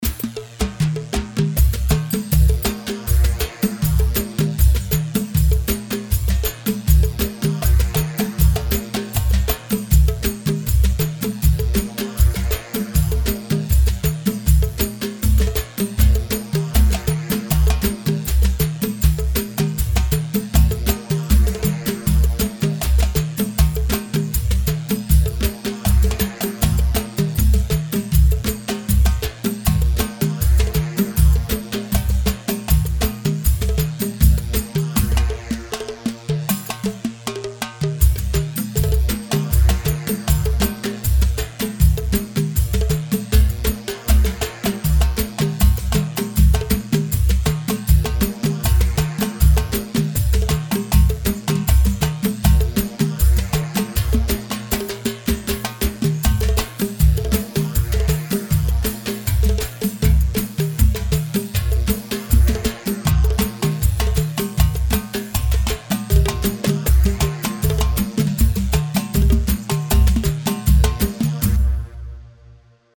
Khbeiti 4/4 158 خبيتي